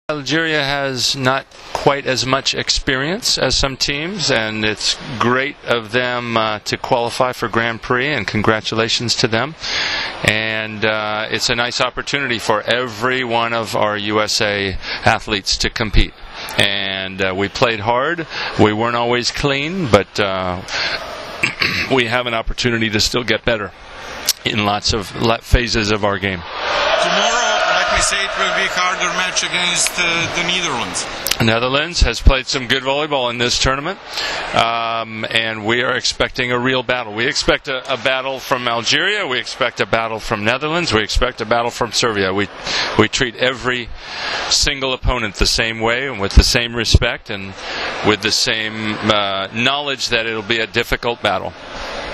IZJAVA KARČA KIRALJA, TRENERA SAD